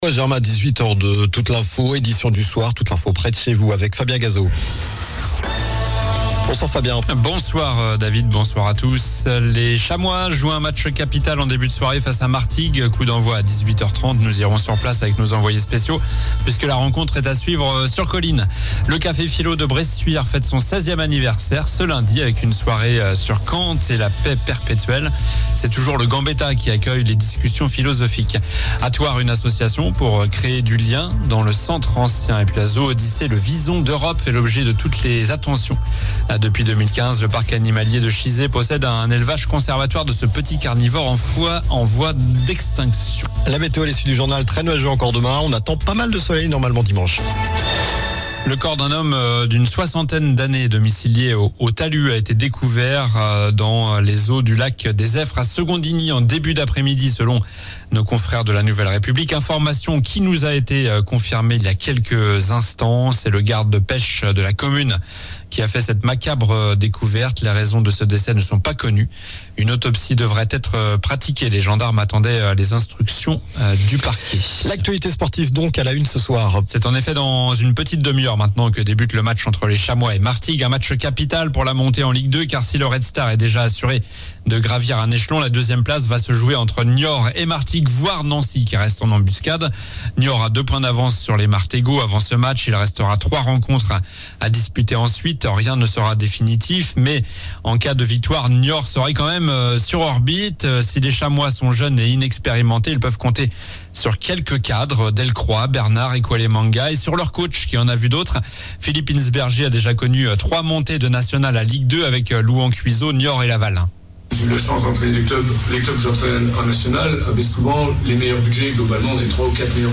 Journal du vendredi 26 avril (soir)